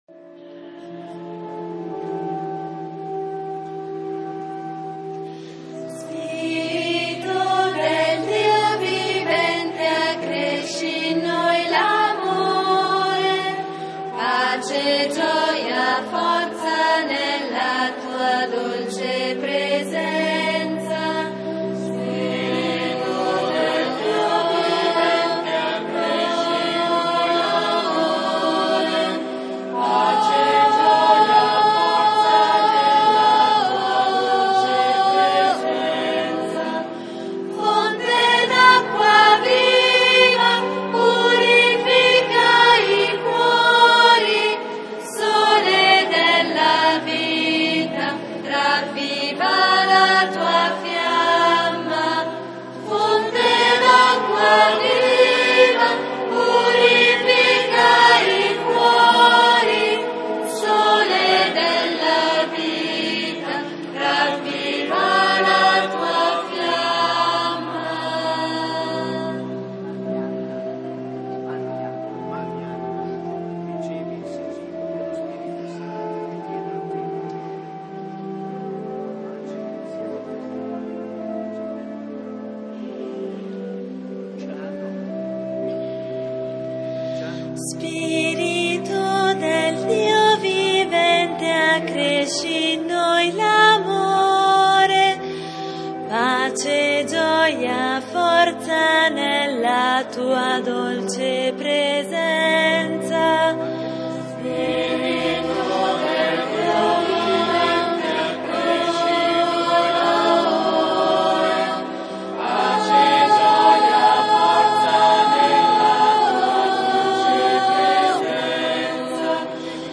IV domenica di Pasqua (Cresime)
canto: